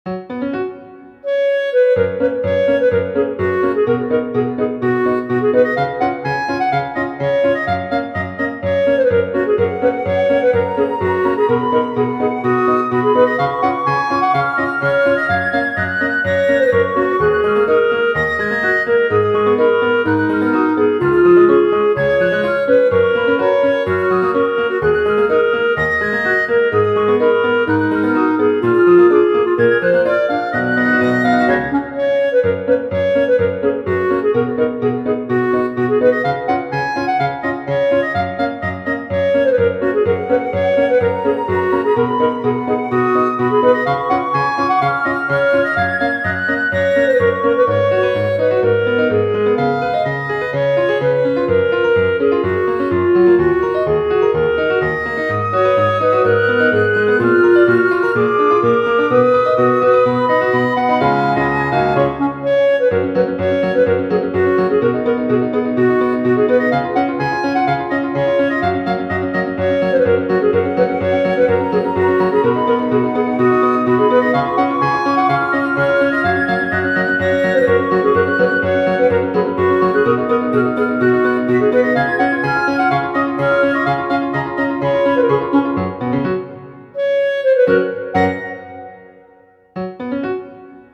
• 軽快なロックや慌てたものなどアップテンポな楽曲のフリー音源を公開しています。
ogg(L) 軽やか ポップ 楽しい
ポップなピアノとクラリネット。